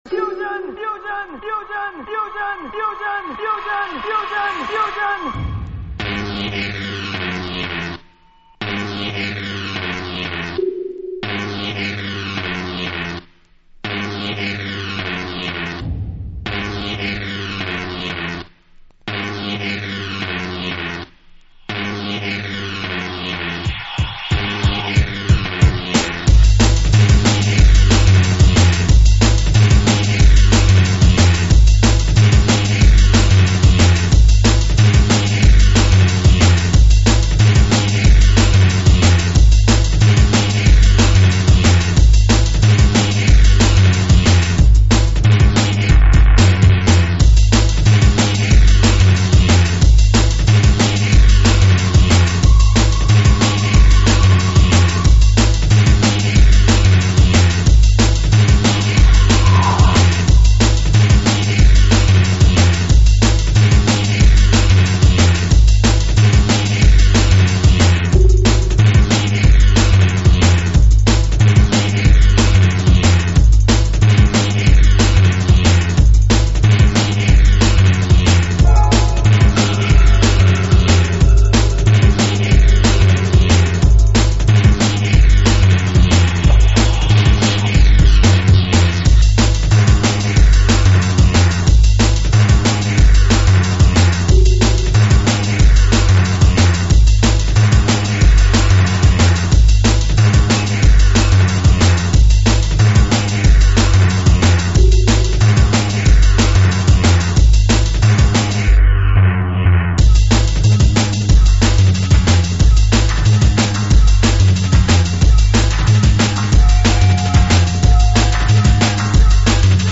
Drum&Bass, Dubstep